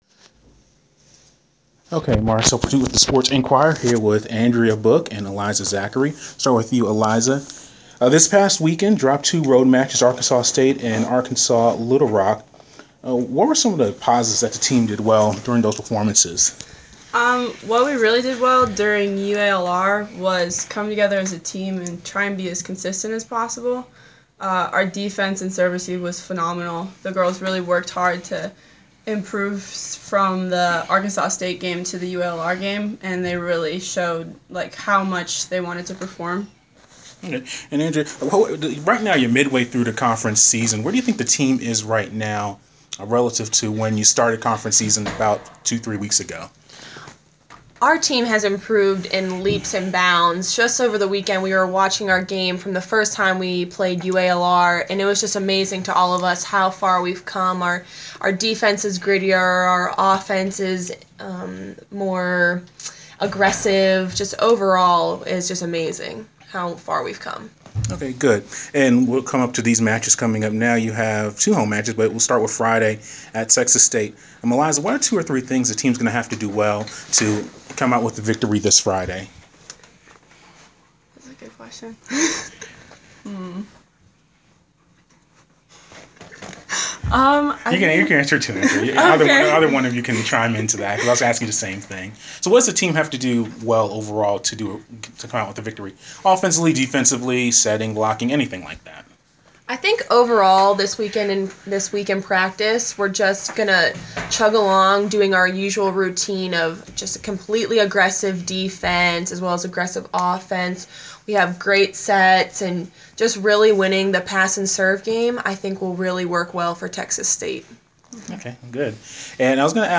Inside Georgia State: Interview